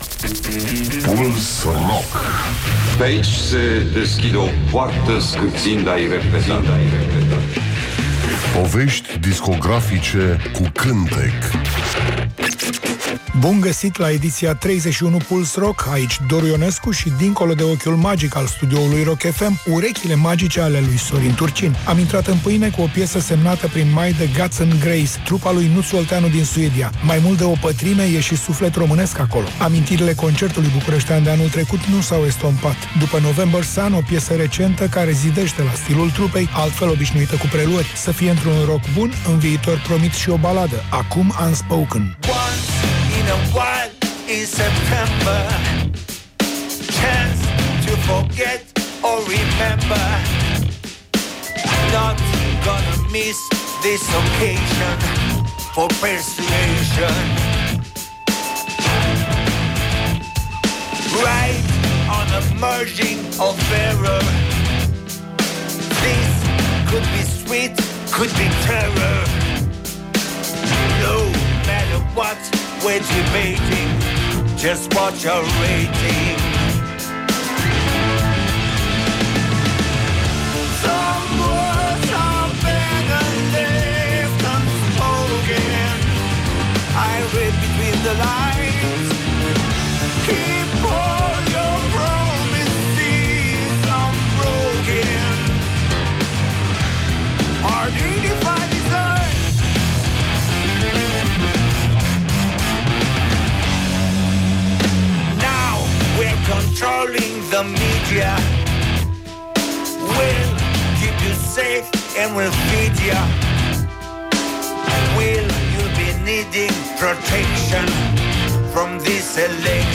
Emisiunea se numește Puls Rock și jonglează cu artiștii noștri rock, folk, uneori chiar jazz.